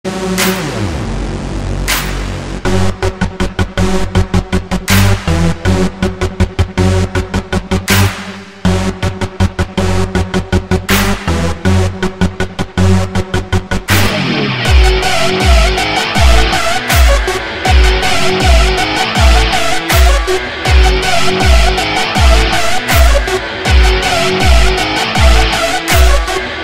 Blog, Tamil Ringtones 26 Sec    update 117 Views